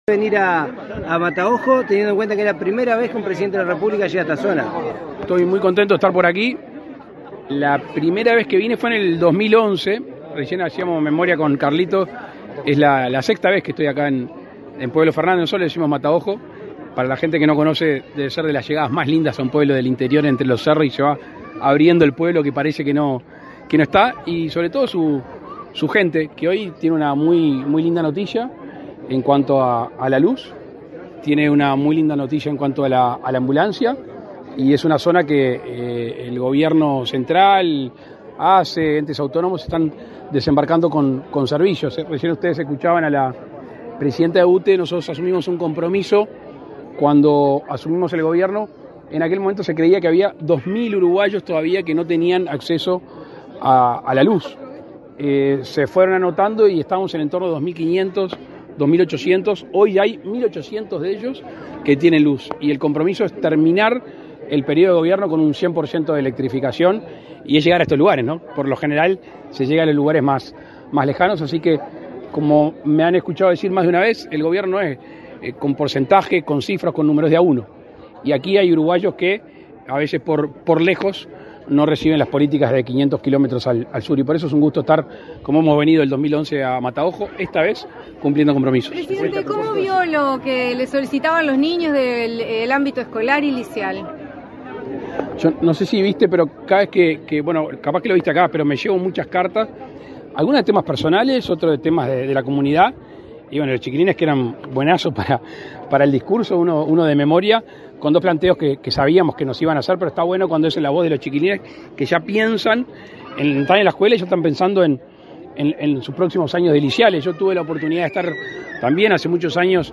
Declaraciones a la prensa del presidente de la República, Luis Lacalle Pou
Declaraciones a la prensa del presidente de la República, Luis Lacalle Pou 16/08/2023 Compartir Facebook X Copiar enlace WhatsApp LinkedIn Tras participar en el acto por el apagado del último generador autónomo de Uruguay, ubicado en el pueblo Fernández, en el departamento de Salto, y la conexión al sistema eléctrico de UTE, este 16 de agosto, el presidente de la República, Luis Lacalle Pou, realizó declaraciones a la prensa.